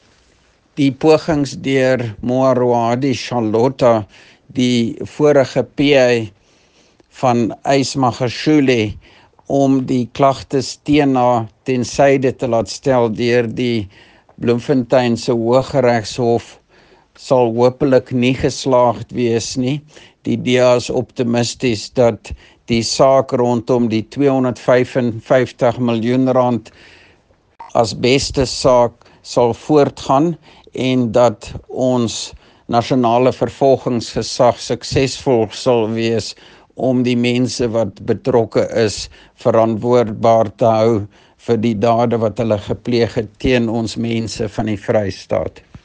Issued by Roy Jankielsohn – DA Free State Leader
Afrikaans soundbites by Roy Jankielsohn MPL